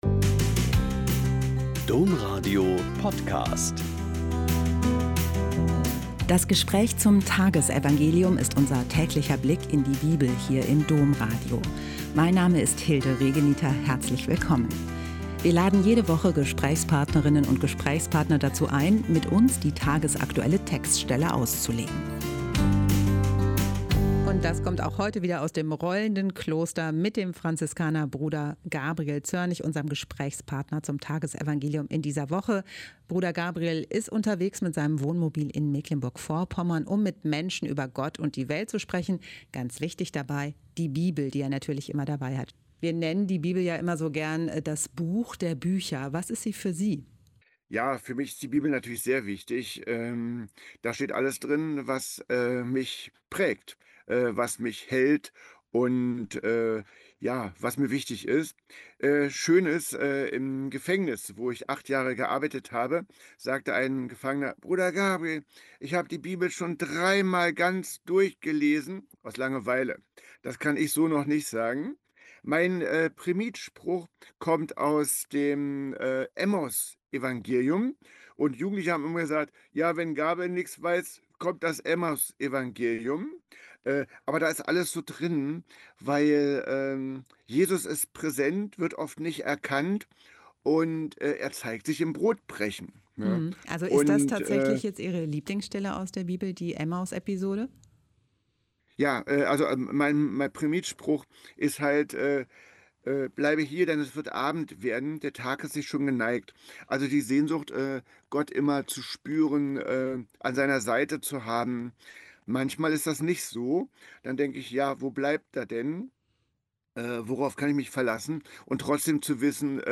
Mt 16,24-28 - Gespräch